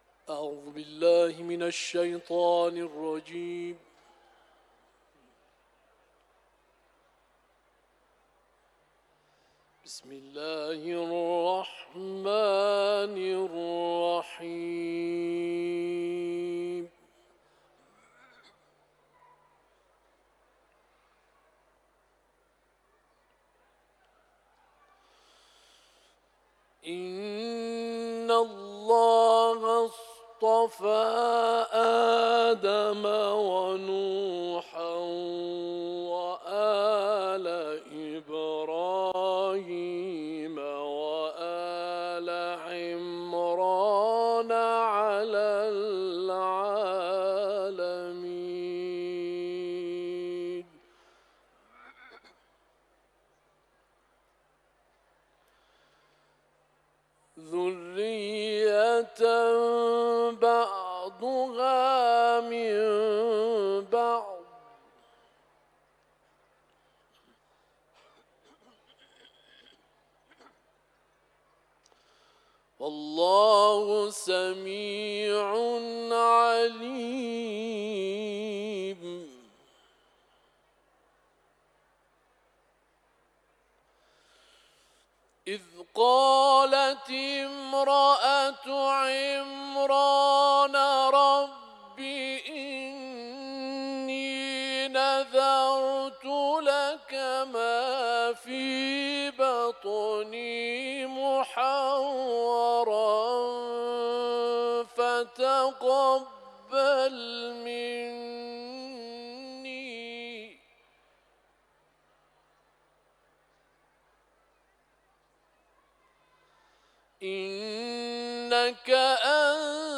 تلاوت
سوره آل عمران ، حرم مطهر رضوی